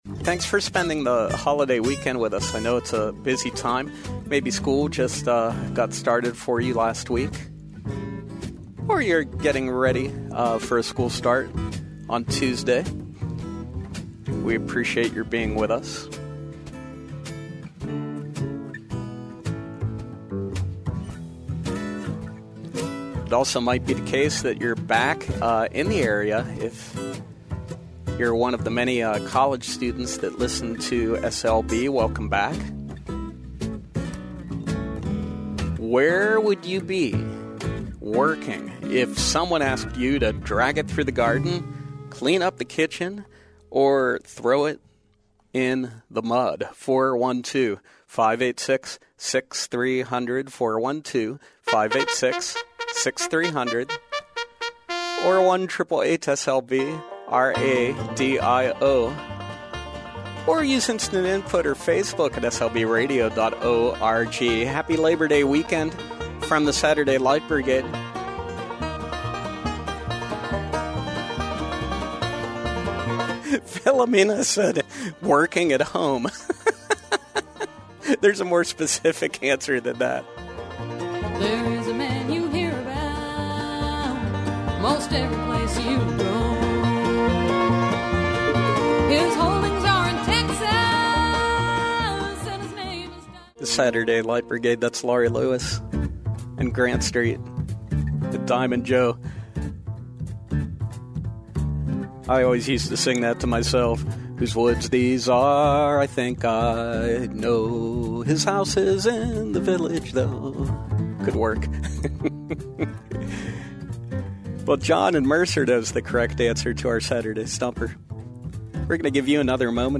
Callers , Conversation